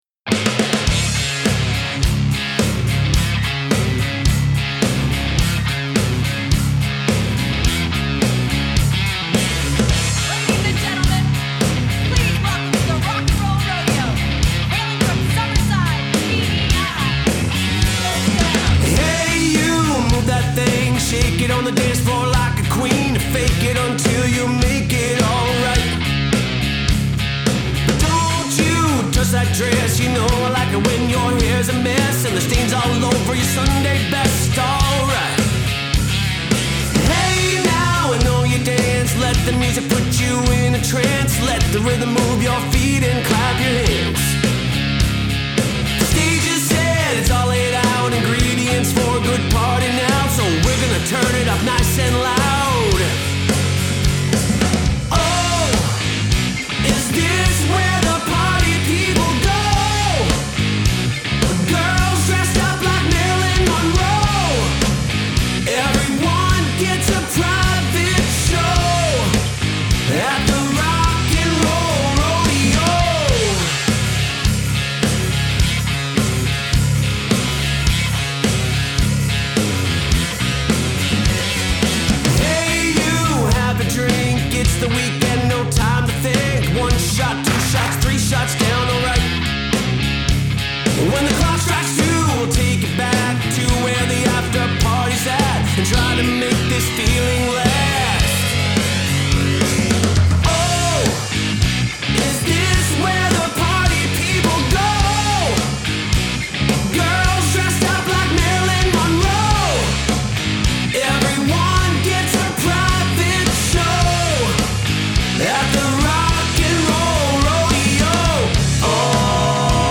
comprised of three talented musicians
guitar and vocals
bass
drums